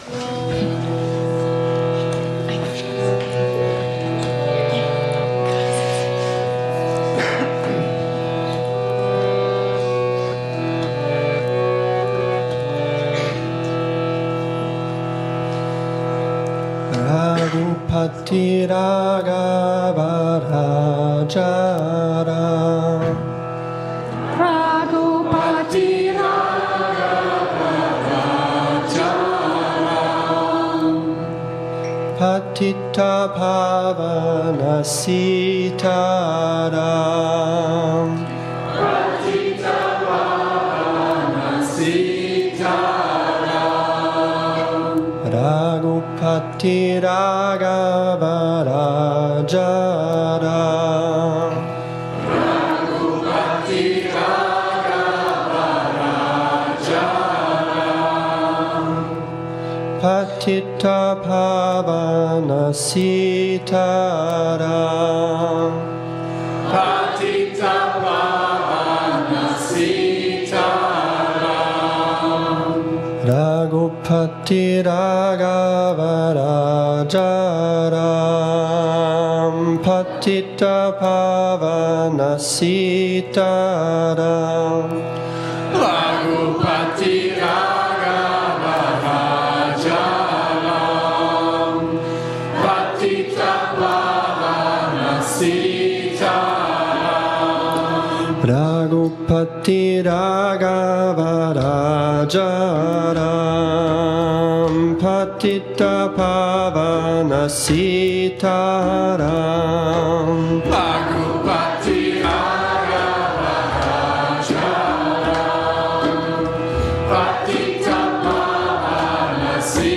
Download - Kailash Ki Shakti Shiva chanted by new yoga instructors | Podbean
A group of new yoga teachers sing in a Saturday evening Satsang at Yoga Vidya in Bad Meinberg, Germany, the kirtan Kailash Ki Shakti Shiva.